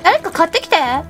Worms speechbanks
Incoming.wav